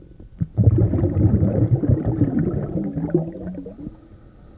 underwater.mp3